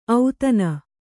♪ autana